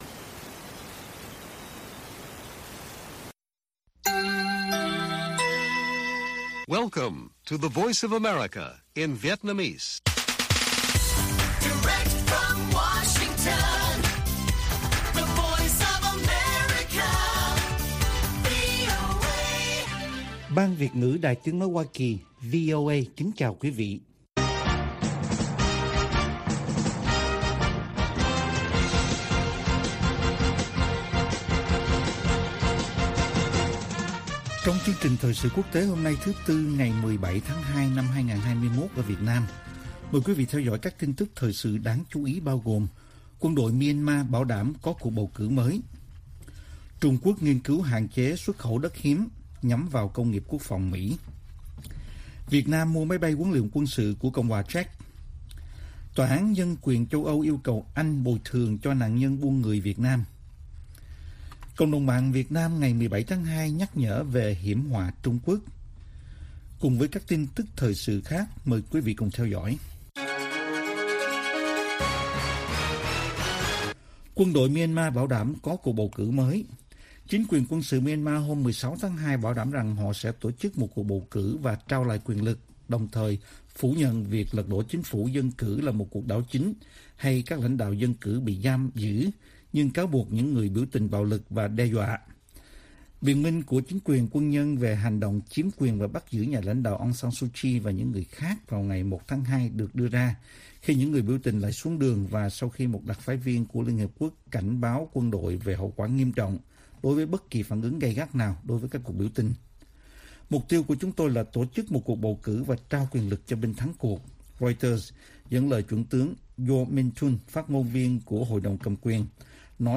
Bản tin VOA ngày 17/2/2021